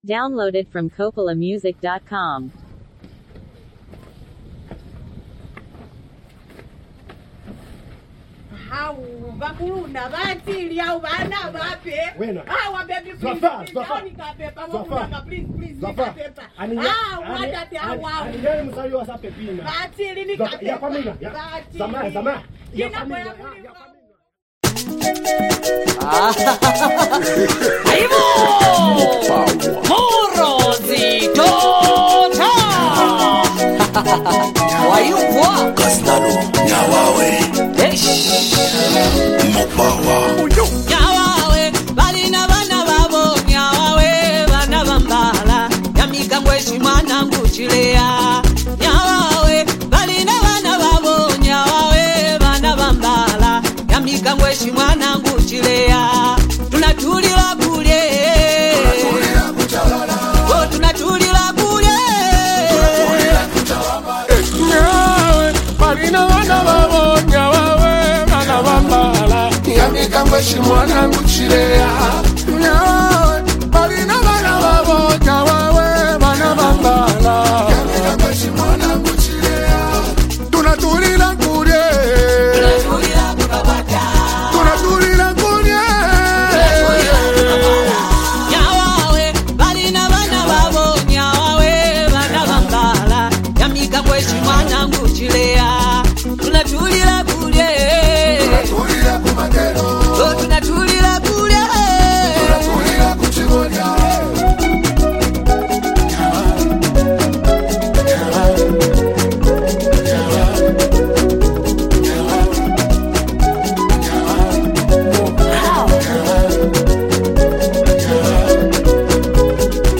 energetic and praise-filled track
The song is built around joyful worship
Supported by uplifting production and a catchy rhythm